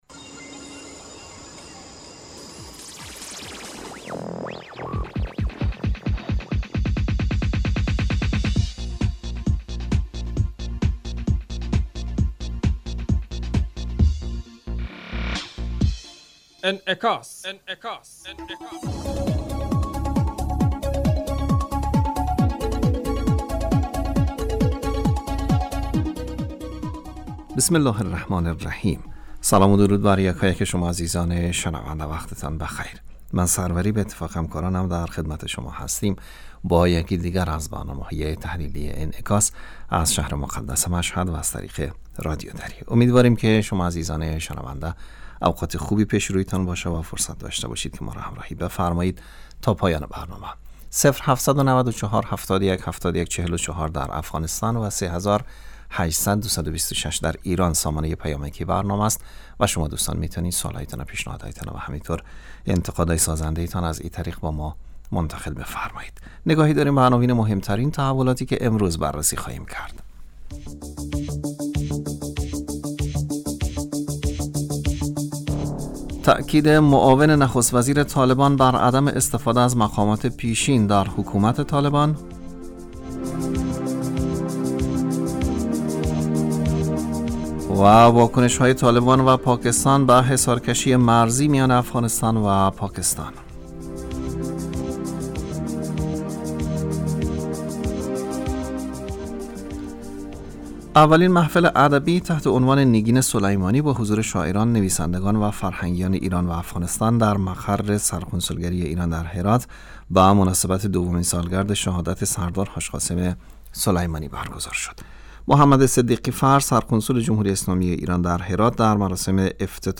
برنامه انعکاس به مدت 30 دقیقه هر روز در ساعت 12:15 ظهر (به وقت افغانستان) بصورت زنده پخش می شود.